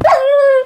sounds / mob / wolf / death.ogg